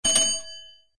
SZ_trolley_bell.ogg